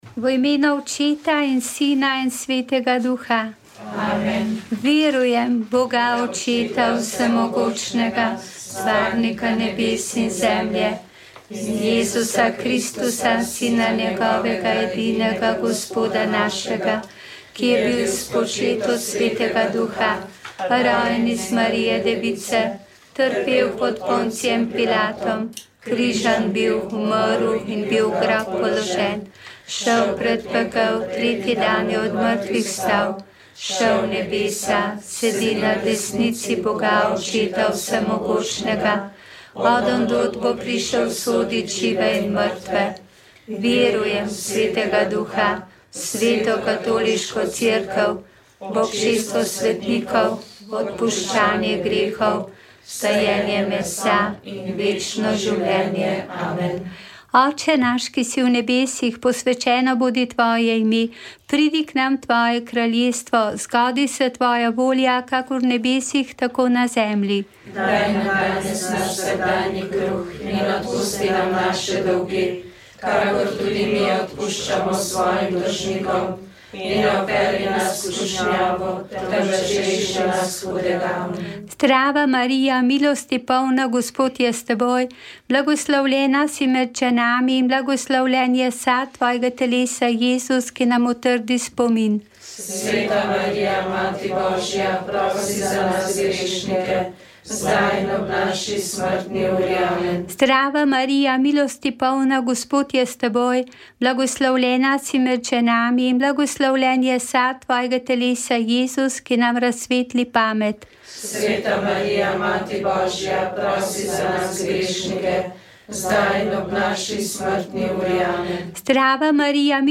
Tokrat smo brali iz zbirke Beckett - Moj advokat, pesnika Zorana Pevca.